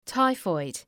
Προφορά
{‘taıfɔıd}